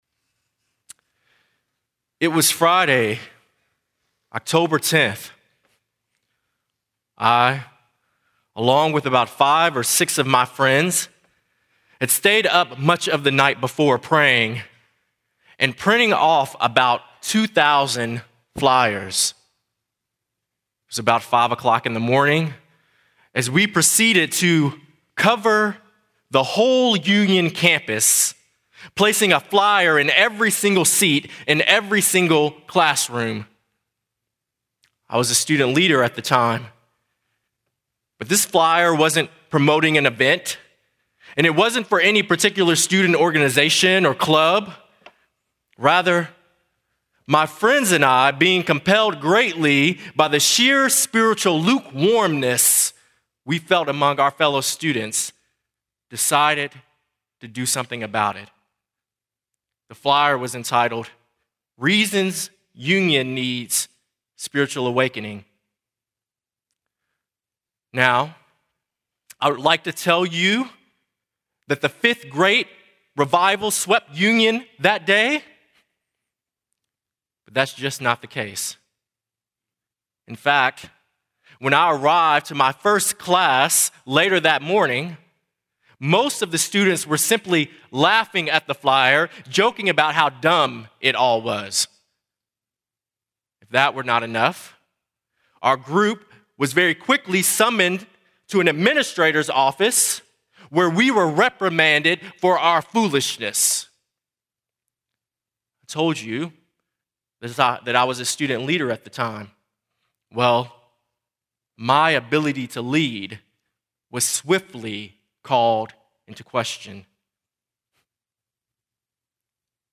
Address: "Do You Believe?" from Romans 3:10-26